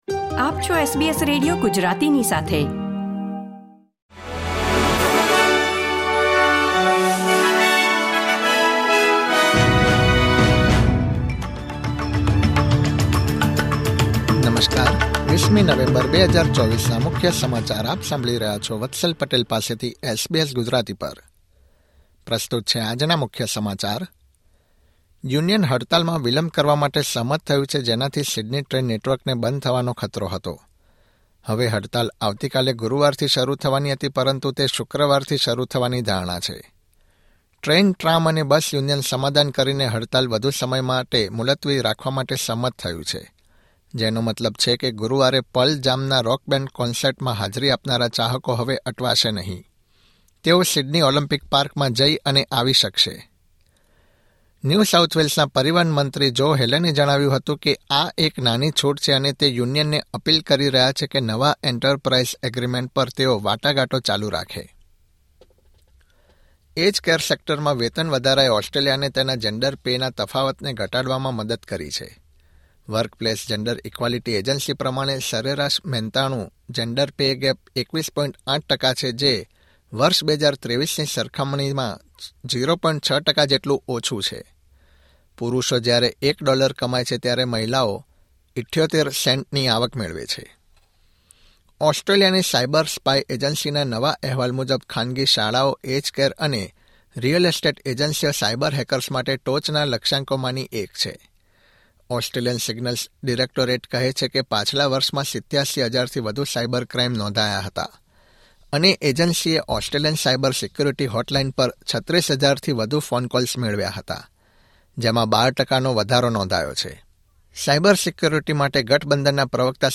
SBS Gujarati News Bulletin 20 November 2024